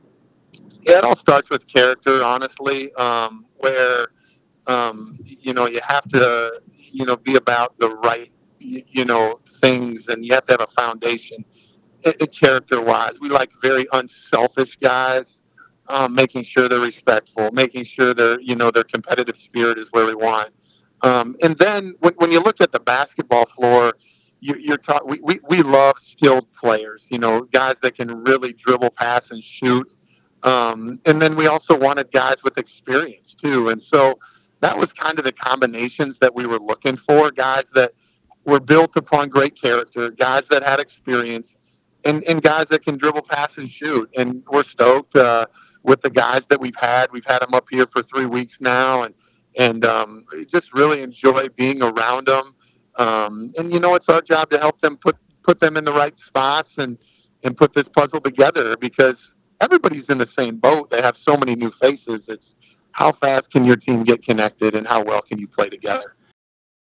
During our interview he repeatedly talks about other people.